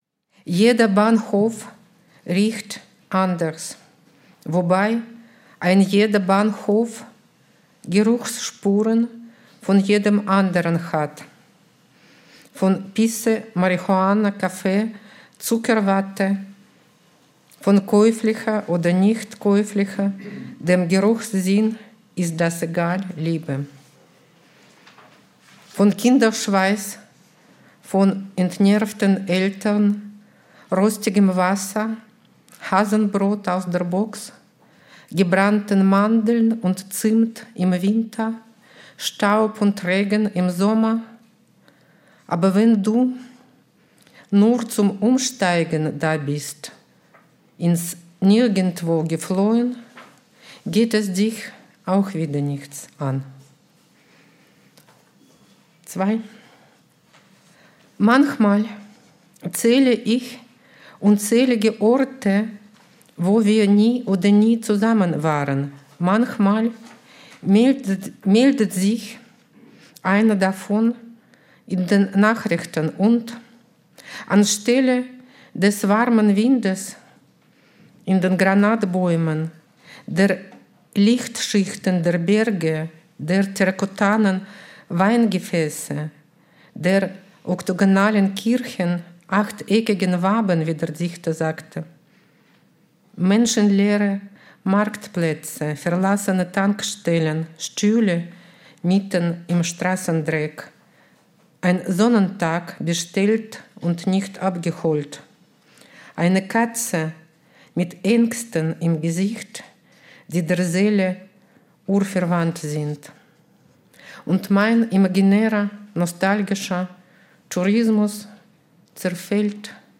Lesung von Olga Martynova
Olga Martynova, Preisträgerin des vergangenen Jahres, liest anläßlich der Preisverleihung 2026 an Nadja Küchenmeister aus ihrem Werk.